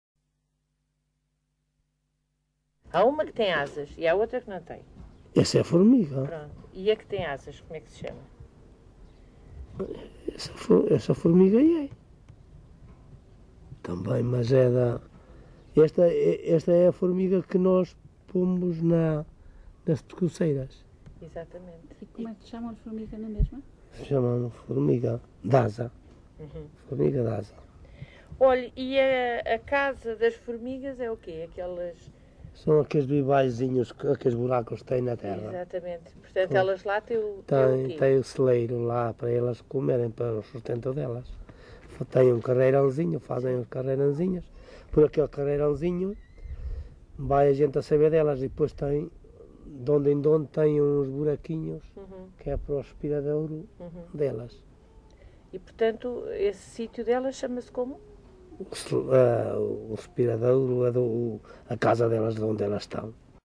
LocalidadeOuteiro (Bragança, Bragança)